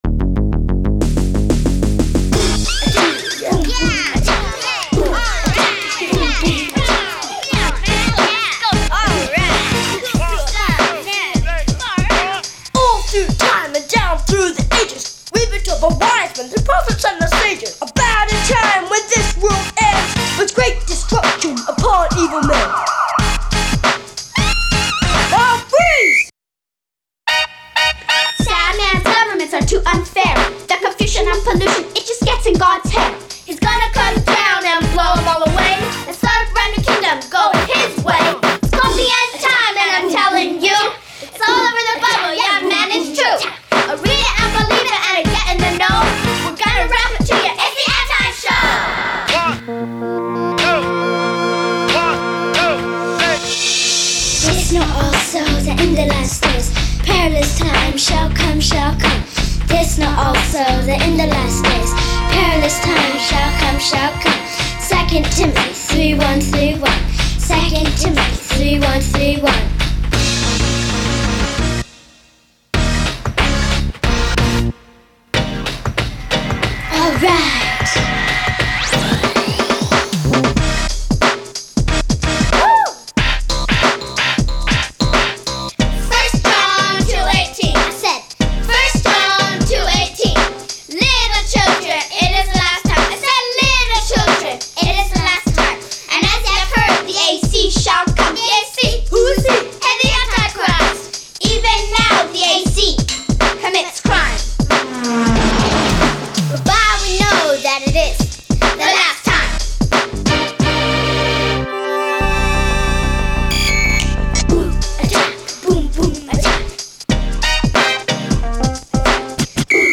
Endtime_Rap.mp3